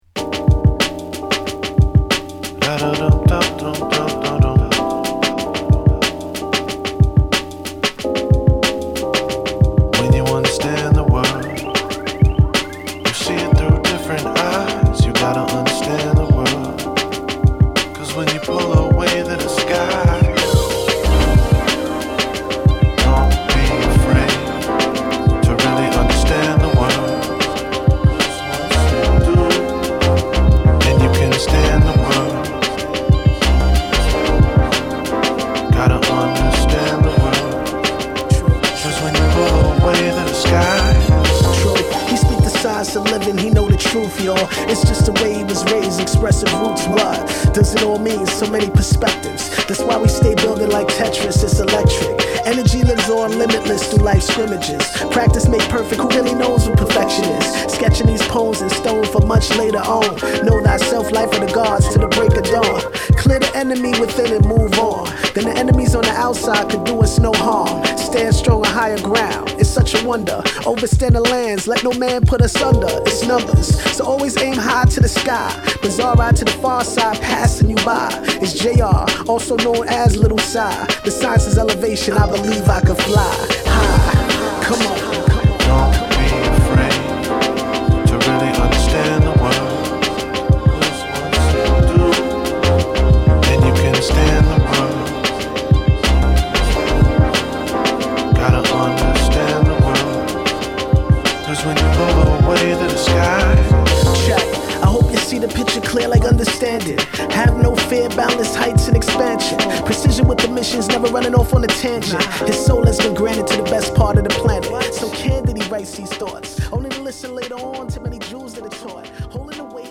(Instrumental)